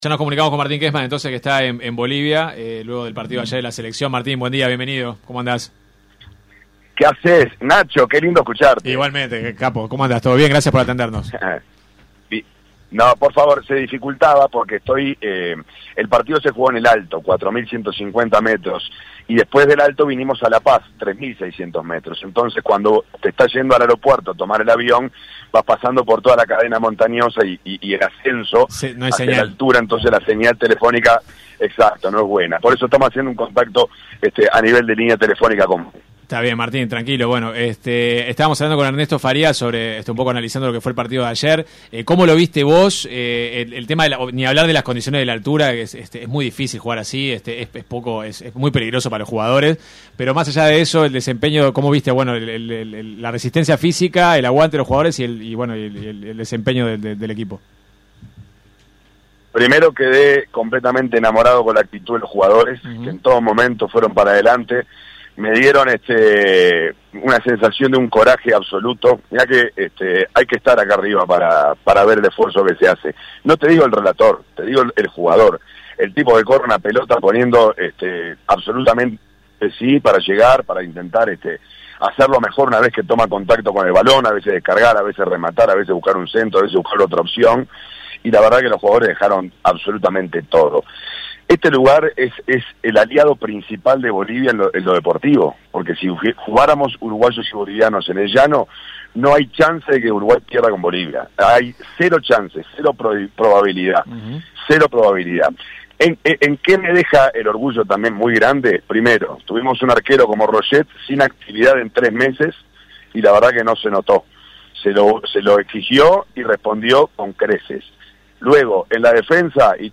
En diálogo con 970 Noticias desde La Paz, elogió la actitud del equipo ante la adversidad de jugar en esas condiciones y reconoció “la inteligencia” de los jugadores y del cuerpo técnico.